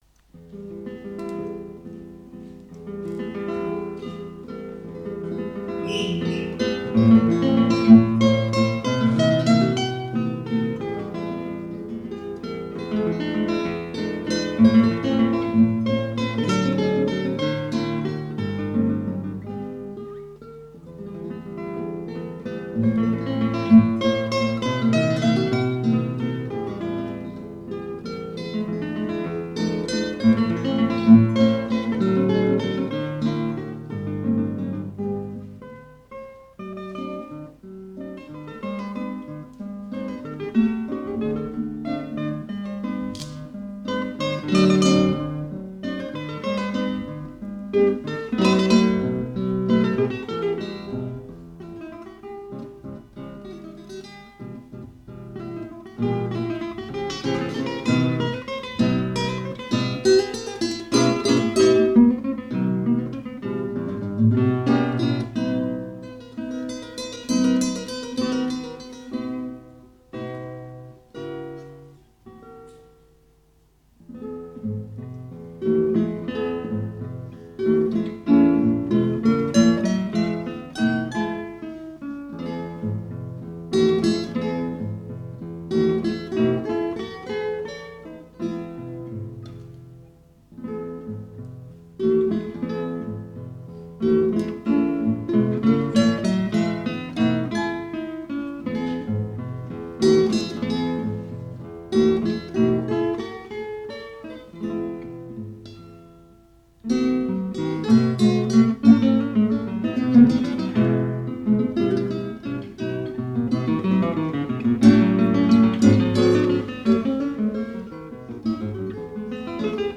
Duo di chitarre | Live recording | InCamTo
Duo di chitarre
Teatro “Le Glicini” Pino Torinese